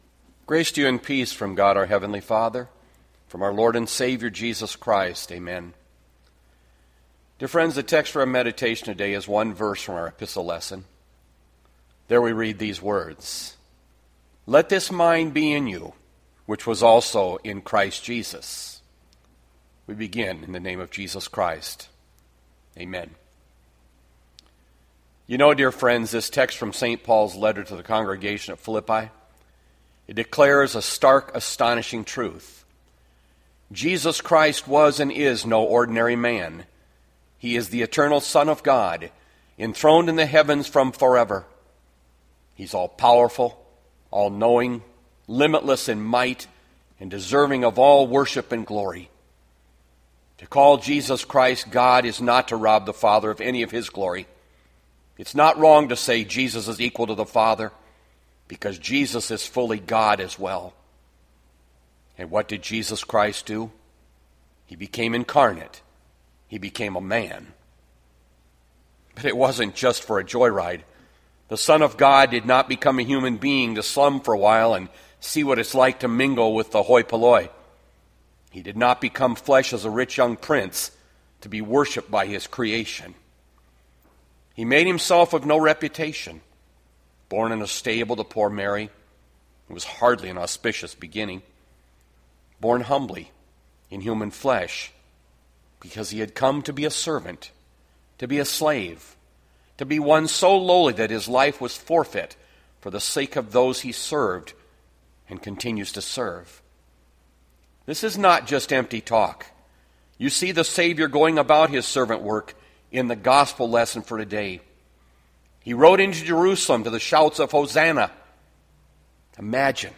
Bethlehem Lutheran Church, Mason City, Iowa - Sermon Archive Apr 5, 2020